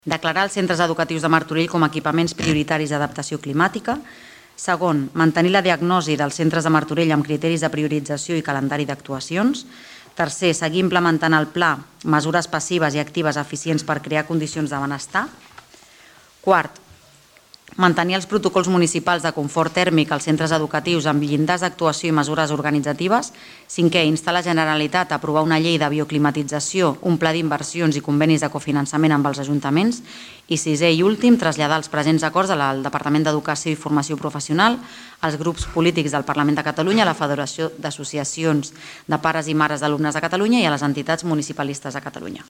Així es va acordar, per unanimitat, en una moció presentada al Ple Municipal d’aquest dilluns.
Laura Ruiz, portaveu de Movem Martorell